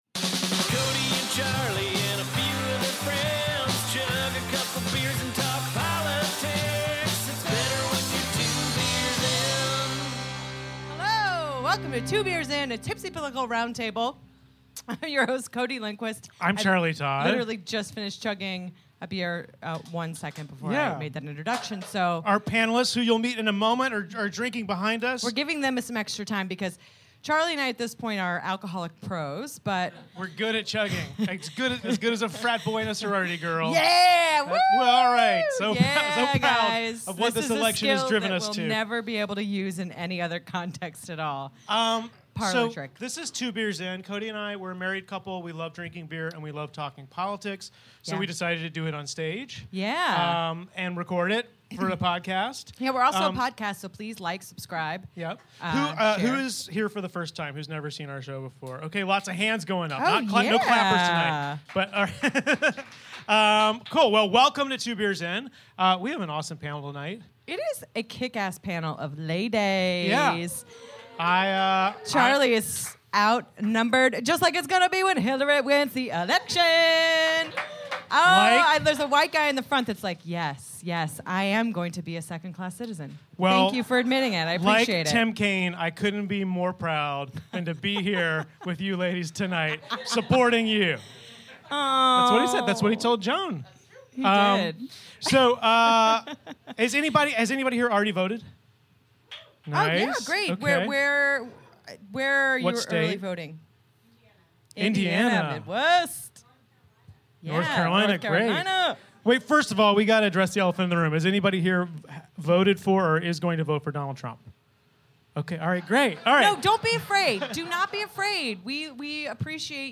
The Nation's Joan Walsh, The Daily Show's Desi Lydic, and Full Frontal's Ashley Nicole Black join us for some beers at our live show at UCB Theatre East.